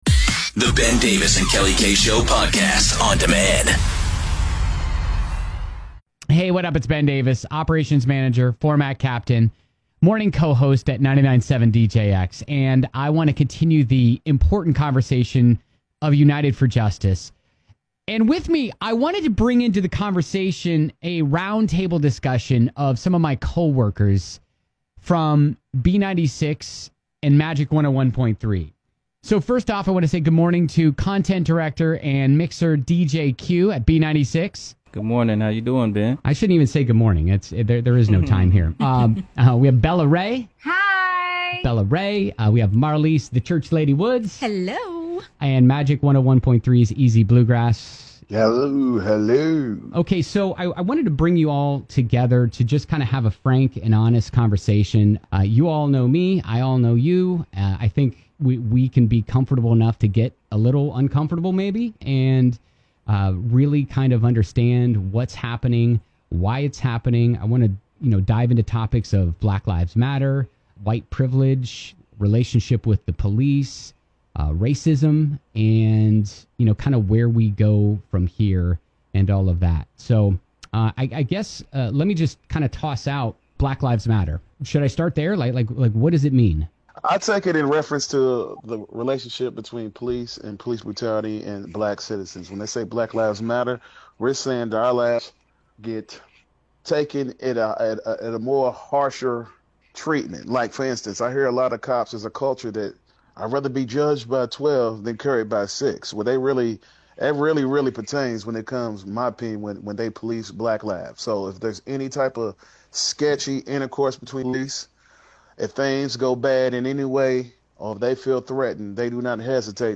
United For Justice: Roundtable Discussion of Race, Inequality, and Policing
It's a discussion among friends of what is happening in the world.